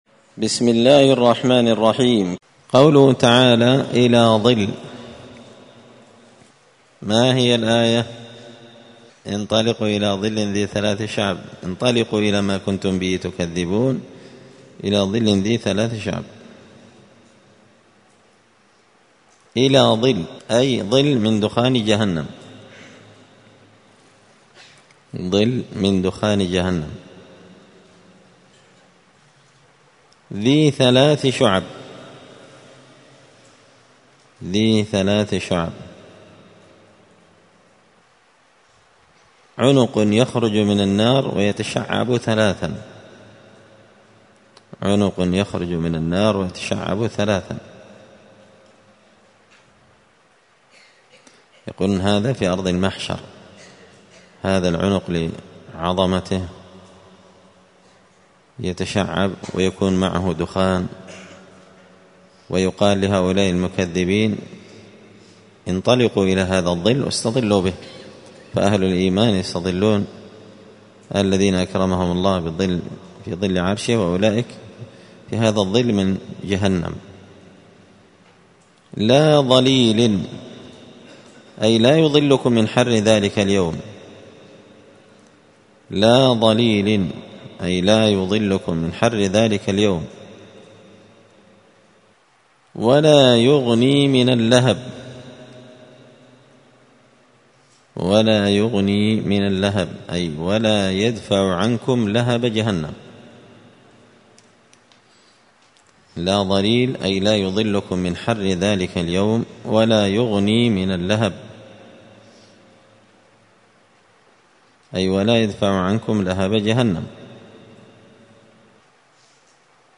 *(جزء تبارك سورة المرسلات الدرس 118)*